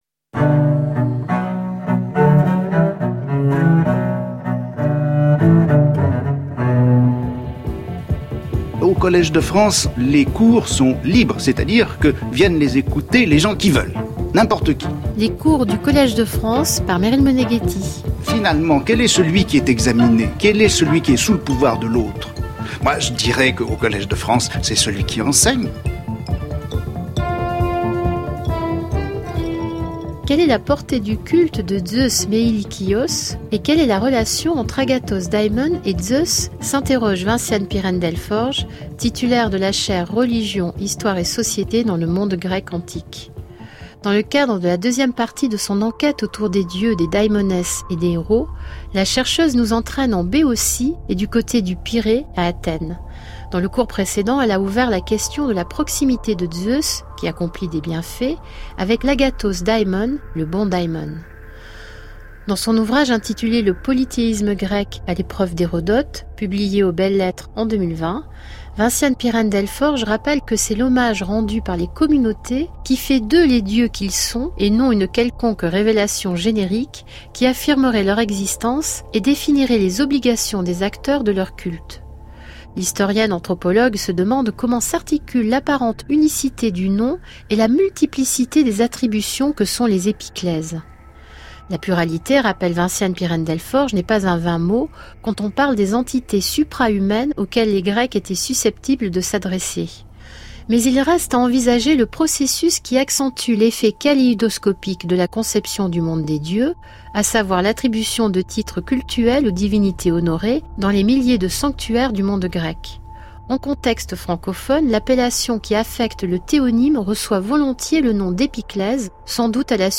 Émission de radio